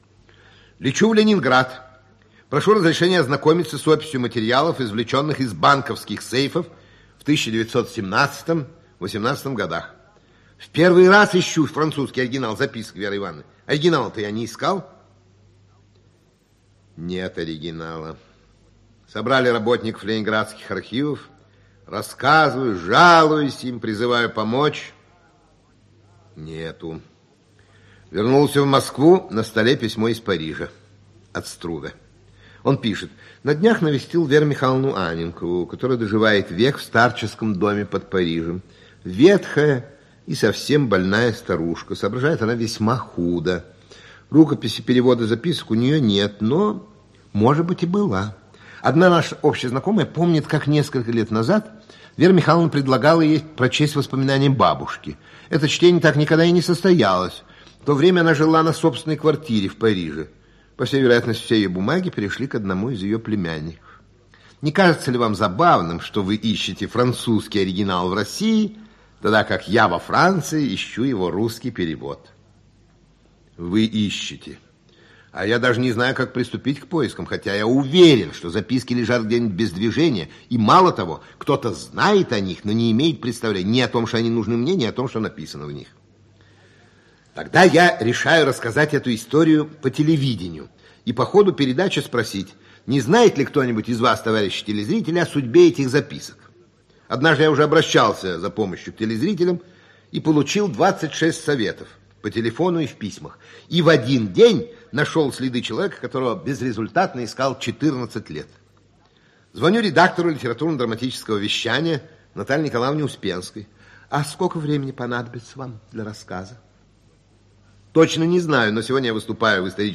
Аудиокнига Утраченные записи Анненковой | Библиотека аудиокниг
Aудиокнига Утраченные записи Анненковой Автор Ираклий Андроников Читает аудиокнигу Ираклий Андроников. Прослушать и бесплатно скачать фрагмент аудиокниги